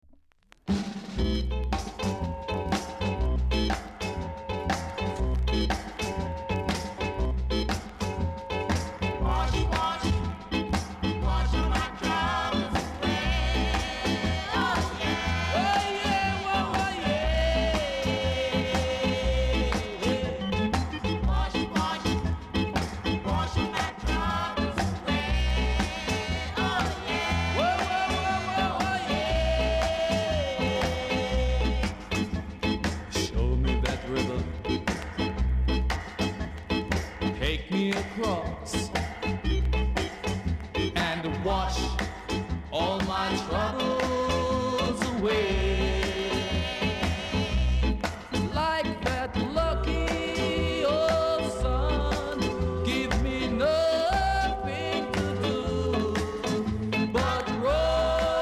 ※チリ、パチノイズが少しあります。